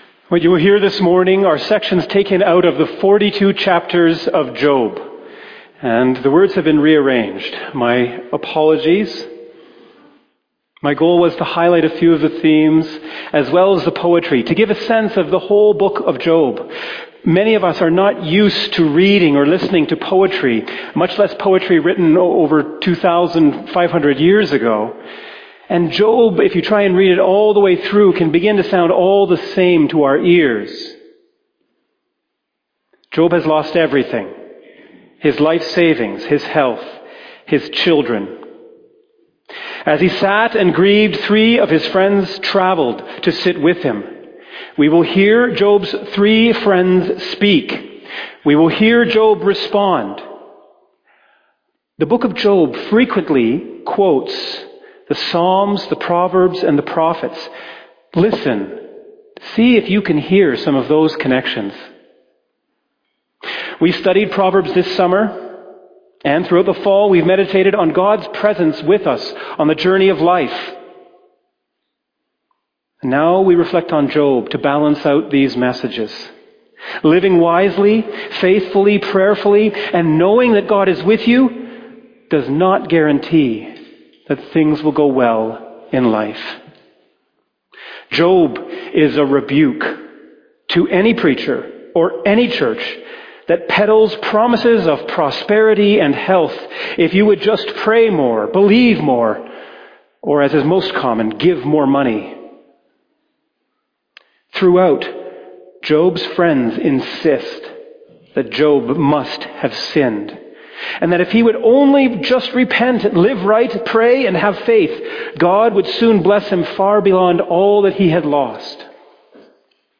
2025 Sermon November 23 2025